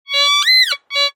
Звук металлоискателя нашел металл